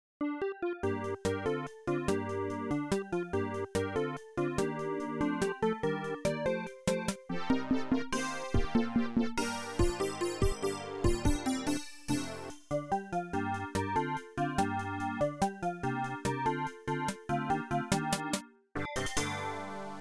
AdLib/Roland Song